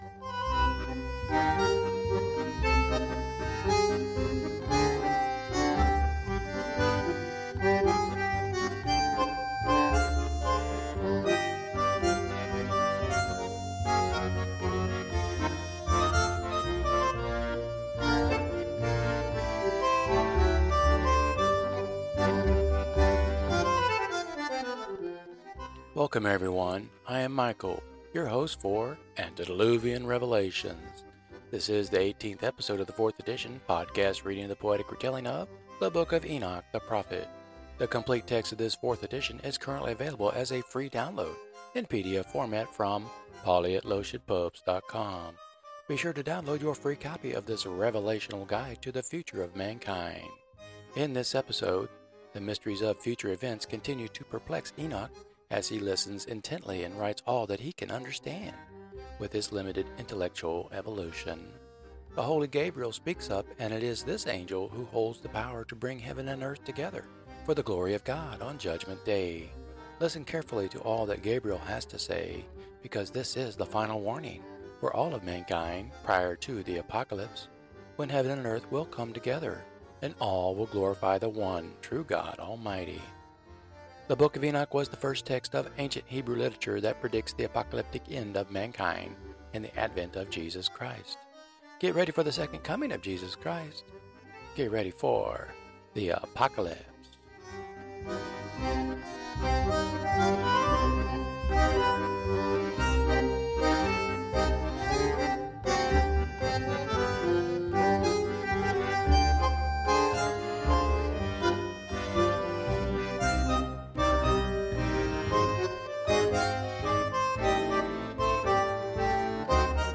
This is the eighteenth episode in this fourth edition of the podcast reading of the poetry within the book. These episodes will present Part Two of the epic poem, and the music selection will get a Jazz and Rock infusion.